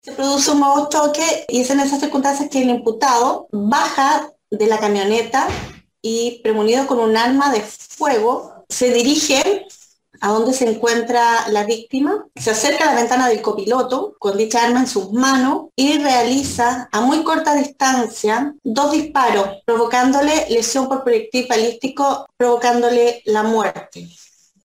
La fiscal de San Antonio, Claudia Cancino, relató el momento en que el imputado disparó en contra de la víctima causándole la muerte por impacto balístico en la cabeza.